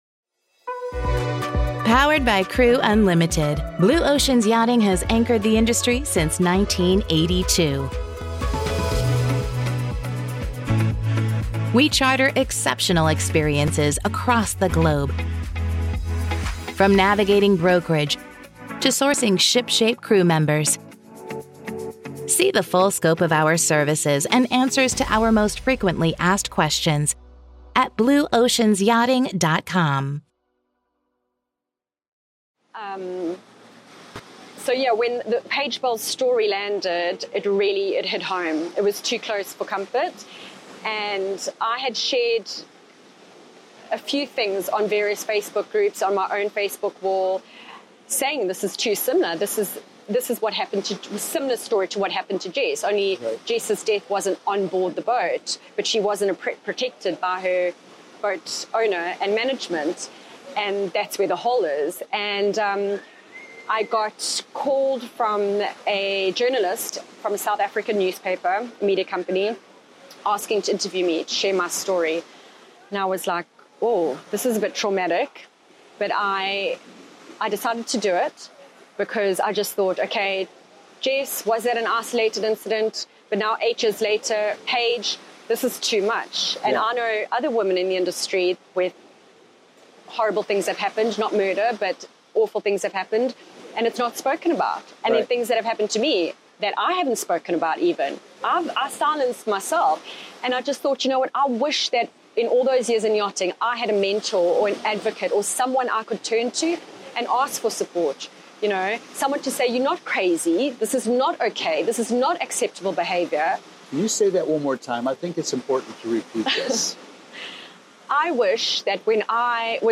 📍 Location Mahekal® Beach Resort — Playa del Carmen, Mexico